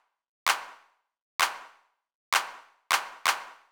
INT Beat - Mix 16.wav